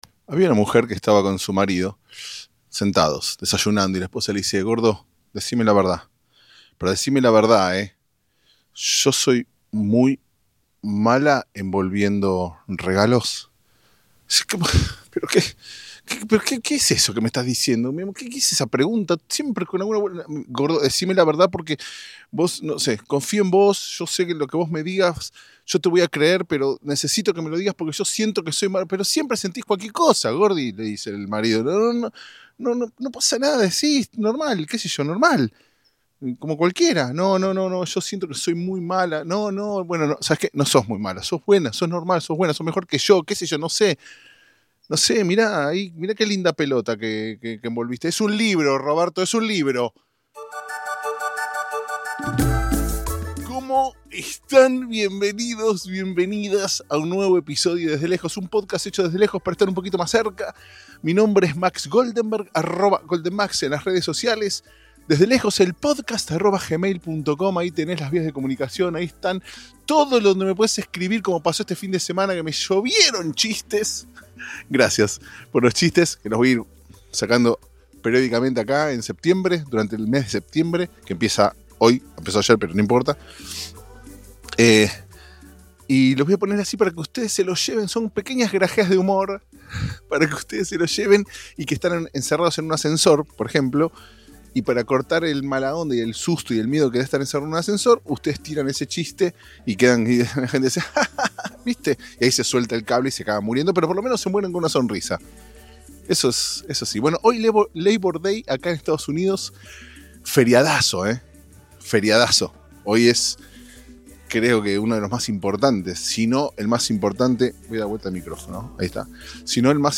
Cantamos, bailamos, te dejo un chiste para que te lleves de regalo y alguna fábula.